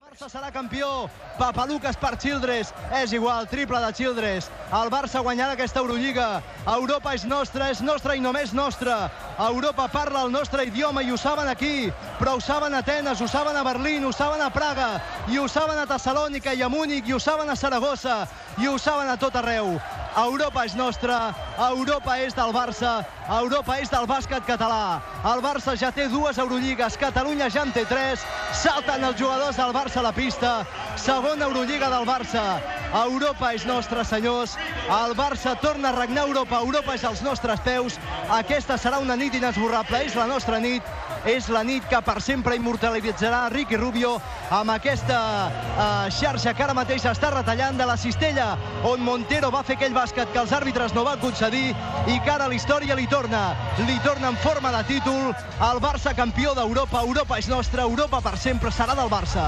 Transmissió dels últims segons de la final de l'Eurolliga de bàsquet masculí que guanya el F.C. Barcelona a l'Olympiacos
Esportiu